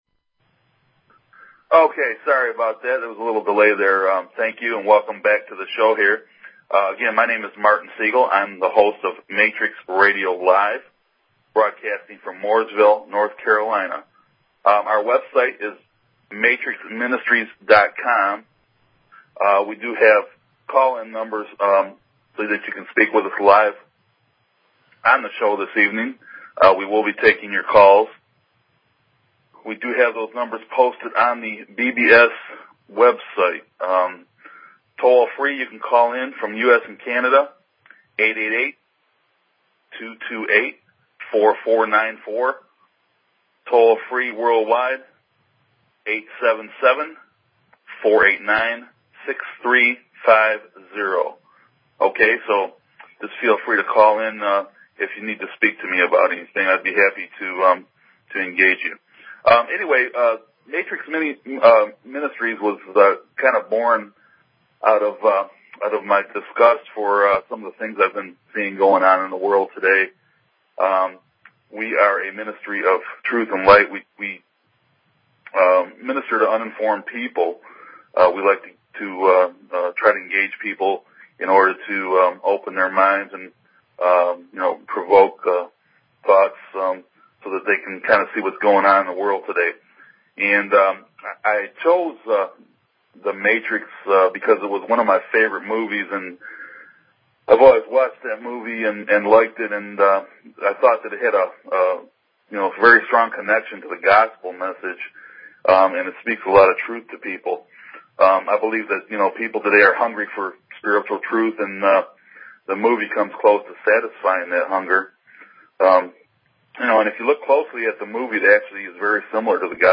Talk Show Episode, Audio Podcast, Matrix_Radio_Live and Courtesy of BBS Radio on , show guests , about , categorized as